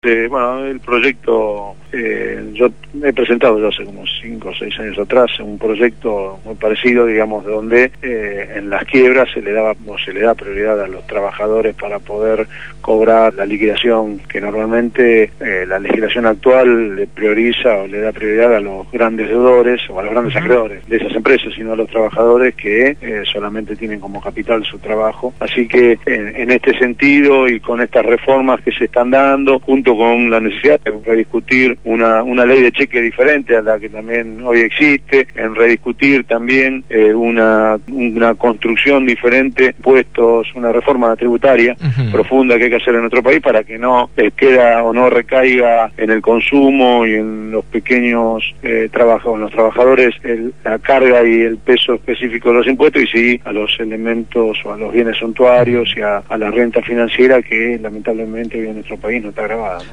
Ariel Basteiro presentó la nueva Ley de Entidades Financieras en Radio Gráfica